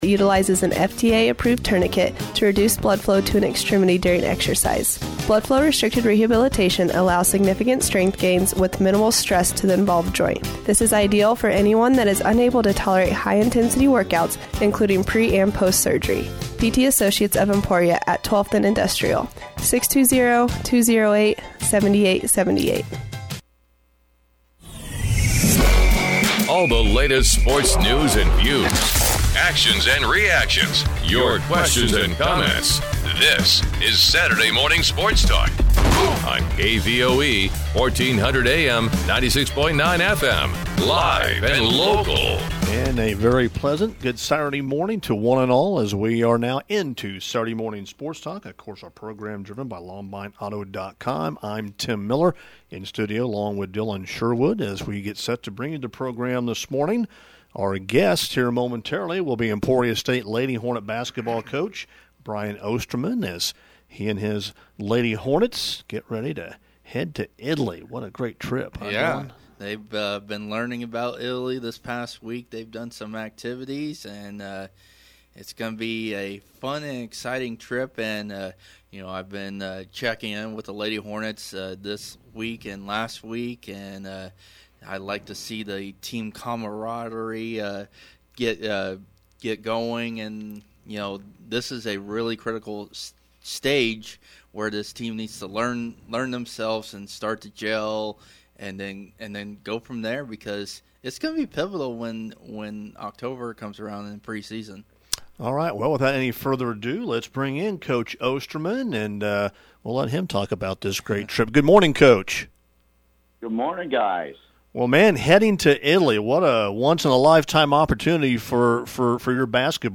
Sports Talk